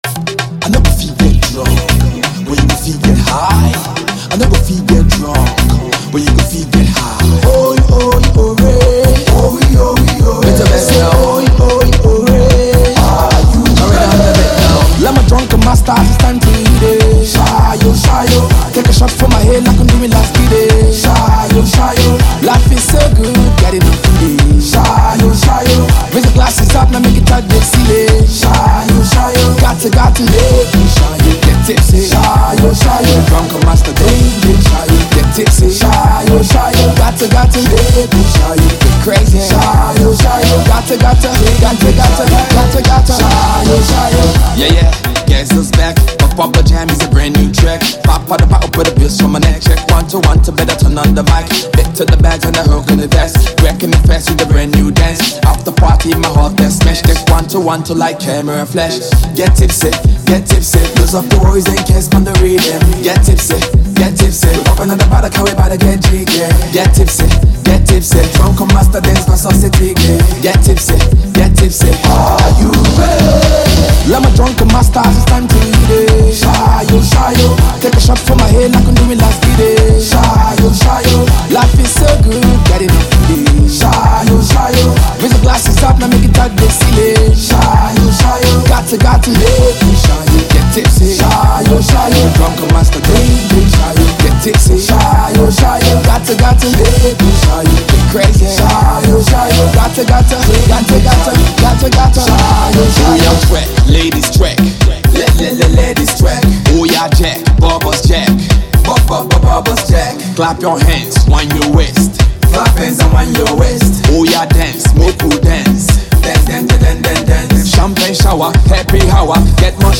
afro-funkified crazy club monster